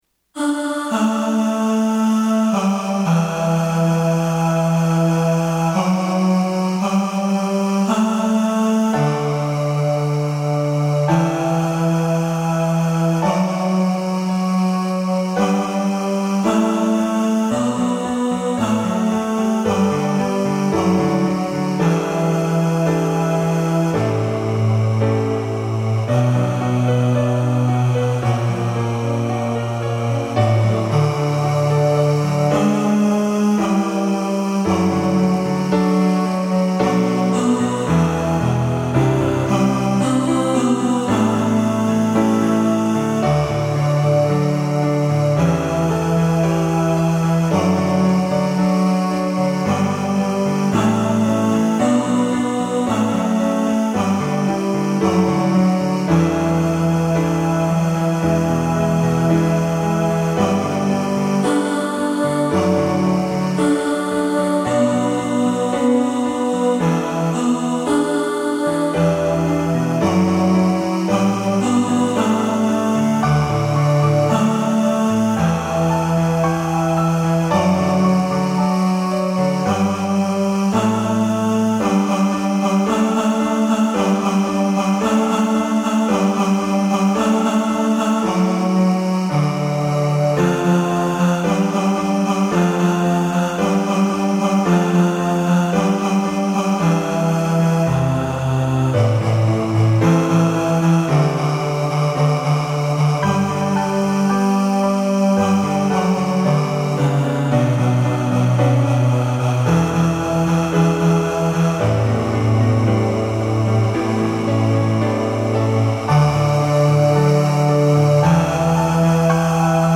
Proud-Of-Your-Boy-Bass.mp3